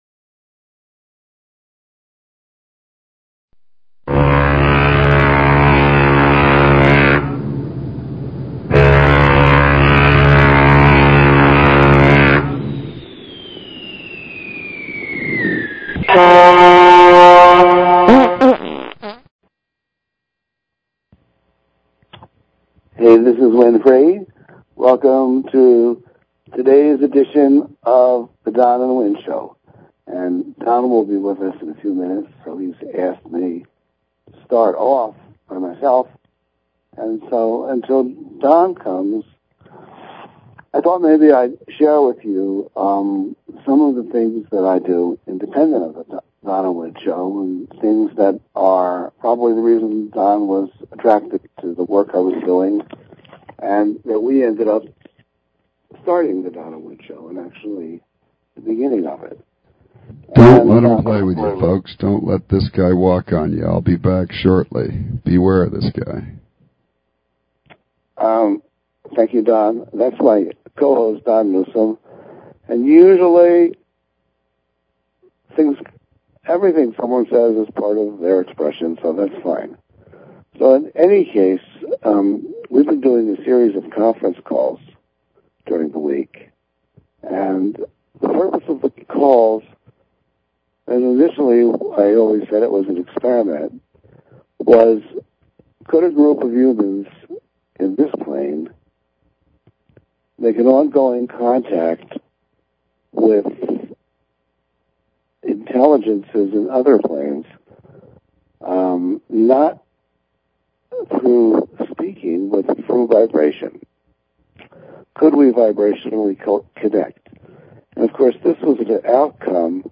Talk Show Episode
Talk Show